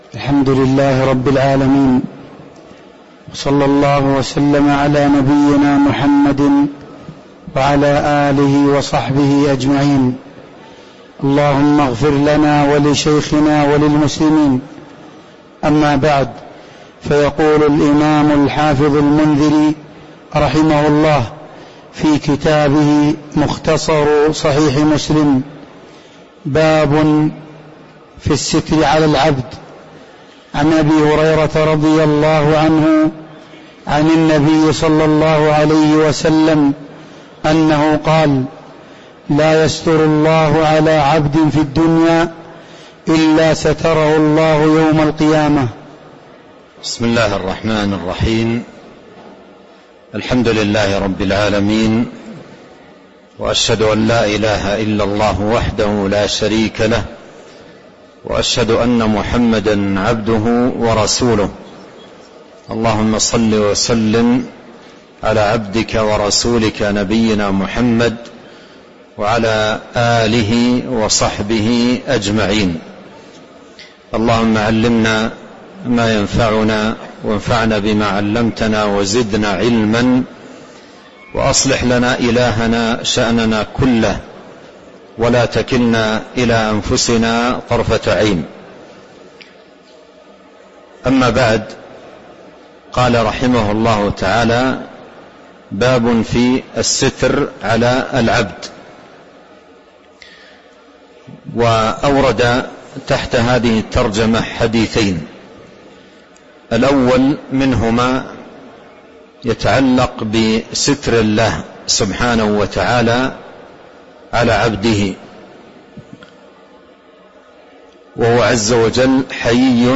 تاريخ النشر ٢٨ رمضان ١٤٤٣ هـ المكان: المسجد النبوي الشيخ